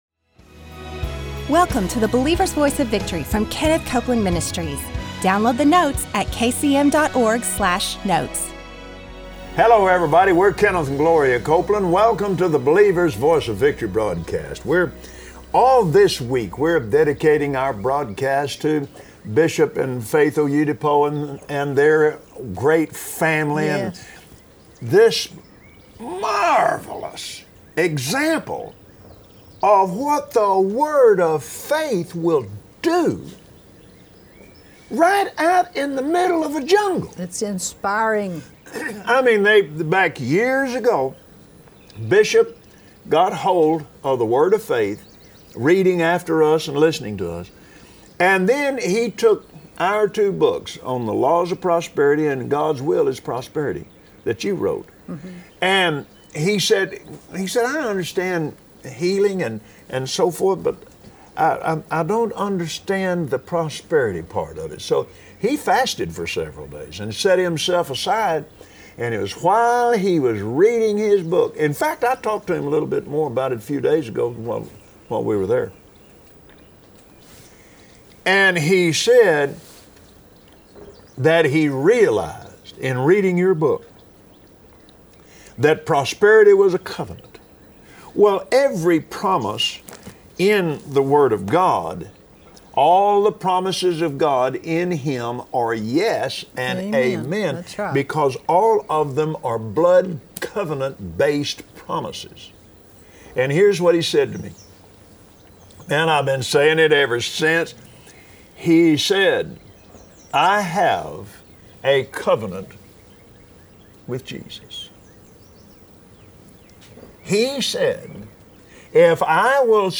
Believers Voice of Victory Audio Broadcast for Wednesday 07/06/2016 Today Kenneth and Gloria Copeland explain to us how anything that is not of faith is sin, and that includes worrying. There is power in the words of Jesus to stop all fear.